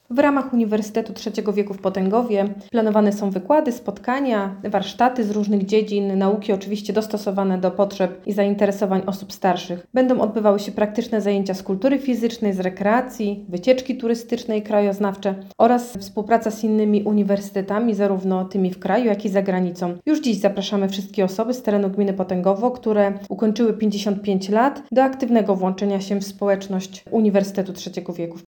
Posłuchaj Anny Bonieckiej, zastępcy wójta gminy Potęgowo: https